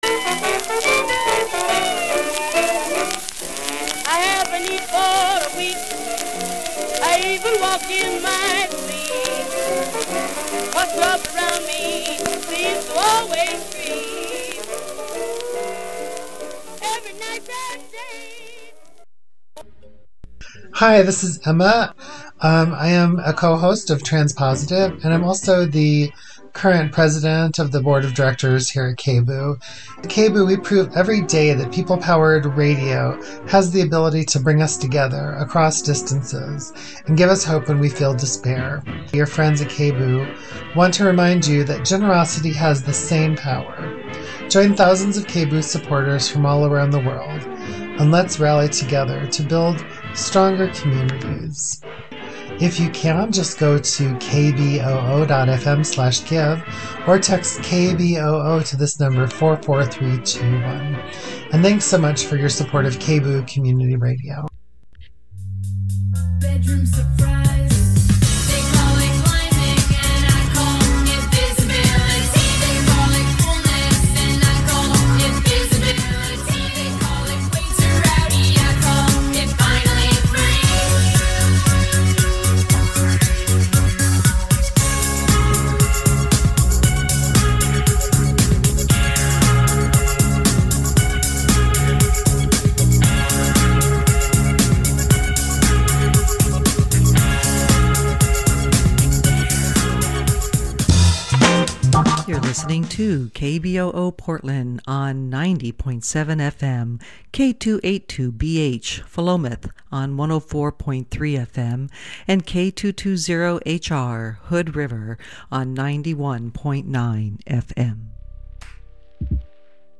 Download audio file Here at KBOO, we will be celebrating LGBTQIA2S+ Heritage on Tuesdays, 7:00 to 8:00pm, with live panels from our studios, where we will have guests from these communities talk to us about their culture, presence, struggles, and relevance.